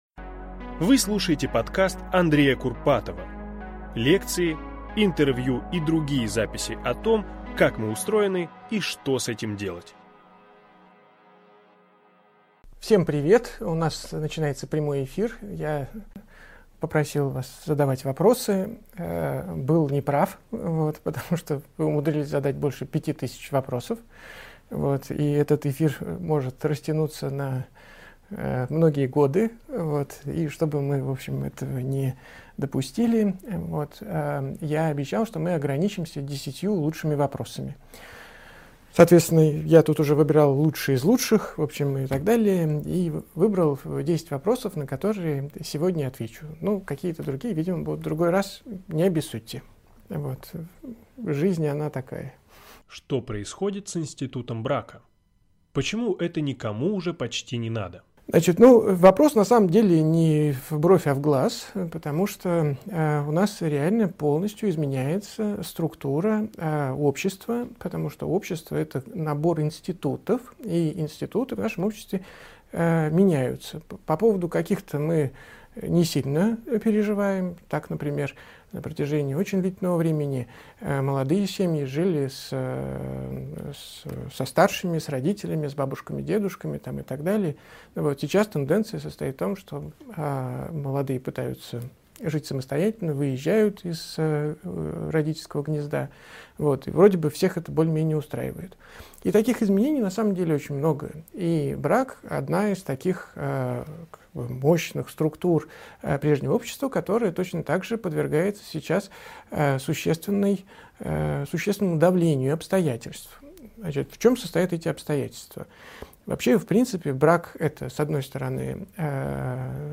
Аудиокнига О современных браках, амбициях и том, как «быть самим собой». Андрей Курпатов отвечает на вопросы | Библиотека аудиокниг